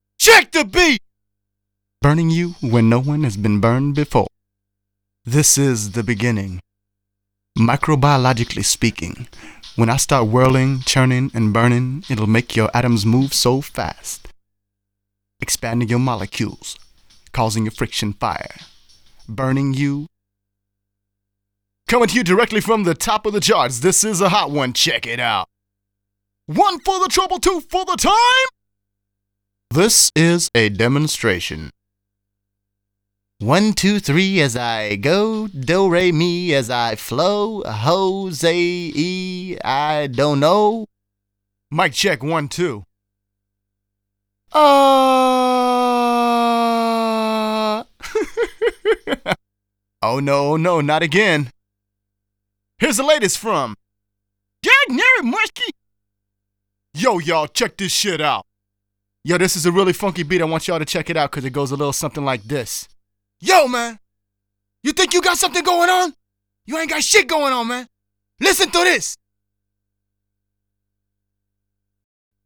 31 Male AdLib 1.wav